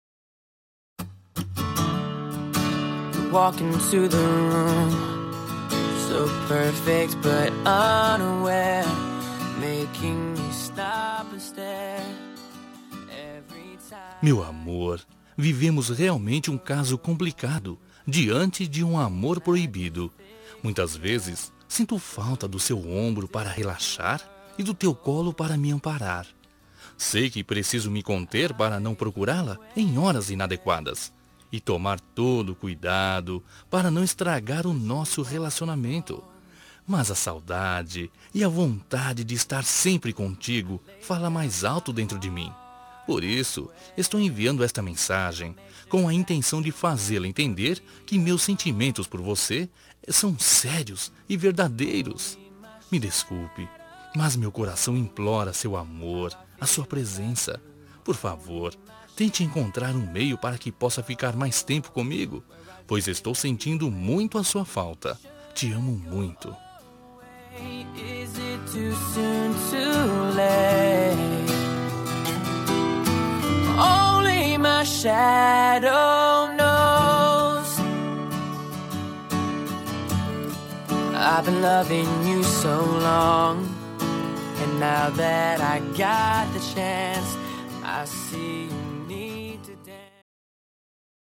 Telemensagem Romântica para Ex. Voz Masculina – Cód: 201666